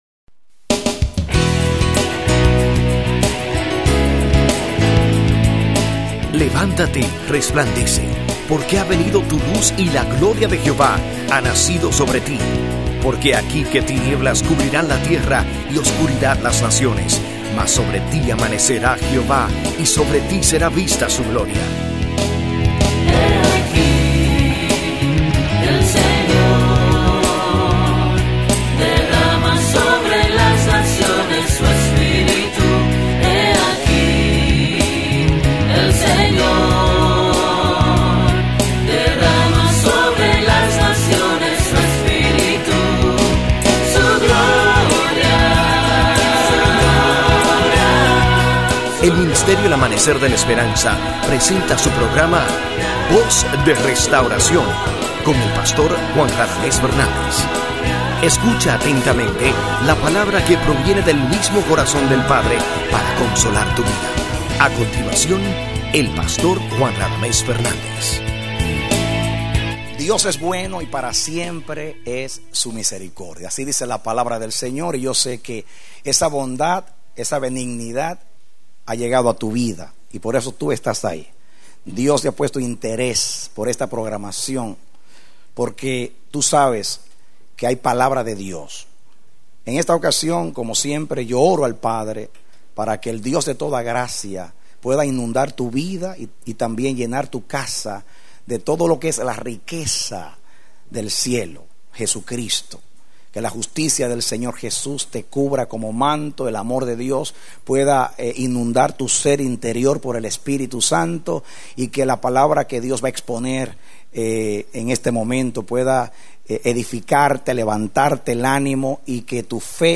Predicado Julio 25, 2004